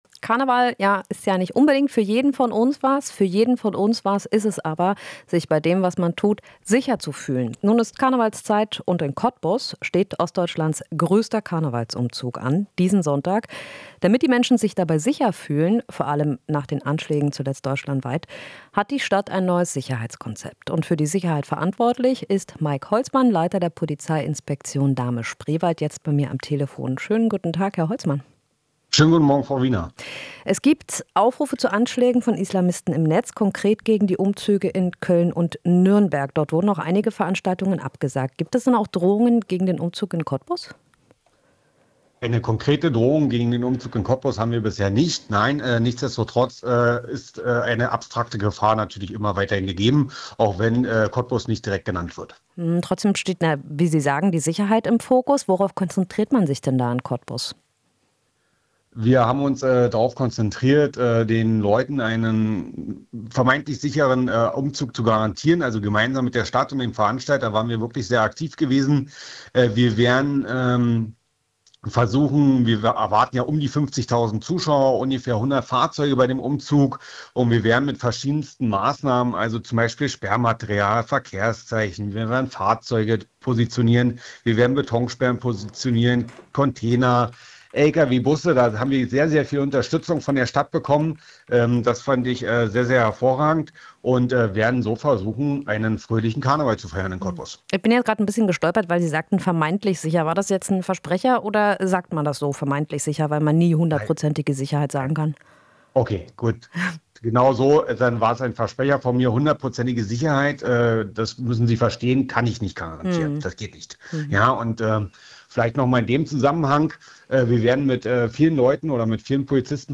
Interview - Karneval: Cottbusser Polizei setzt auf Poller und offene Augen